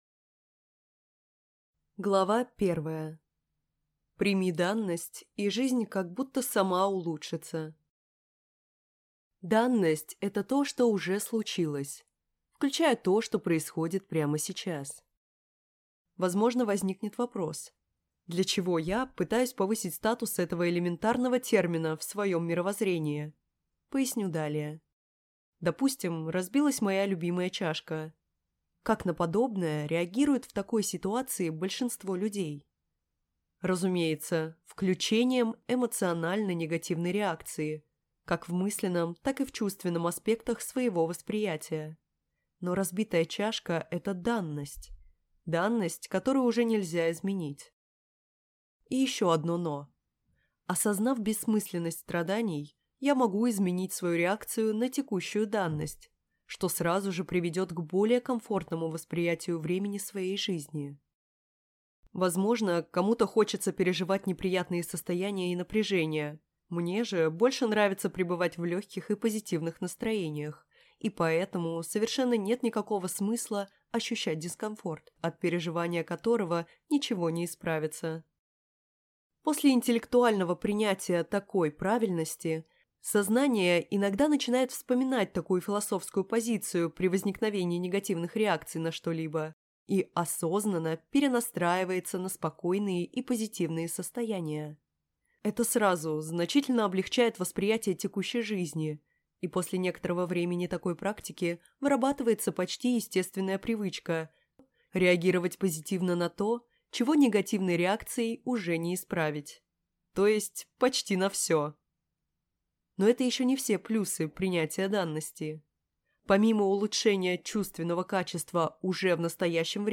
Аудиокнига Из ниоткуда в никогда…